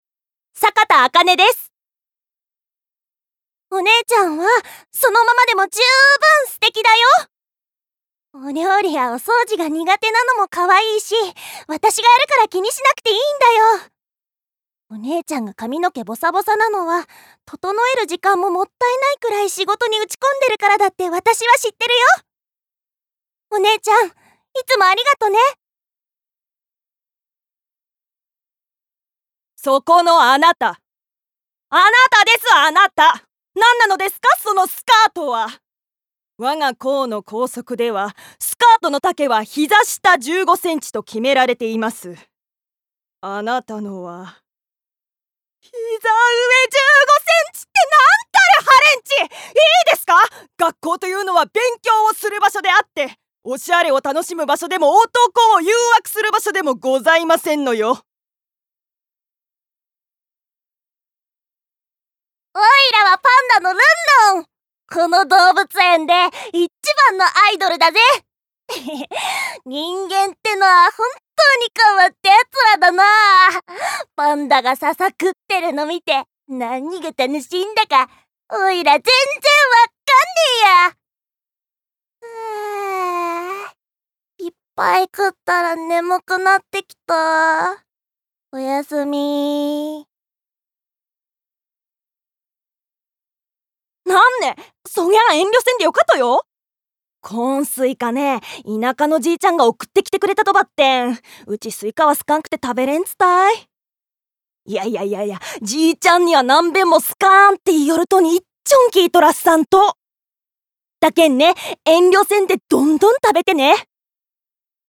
Voice Sample
ボイスサンプル